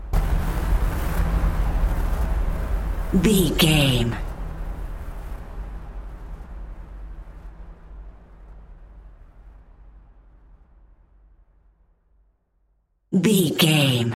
Sound Effects
Atonal
scary
ominous
dark
dramatic
eerie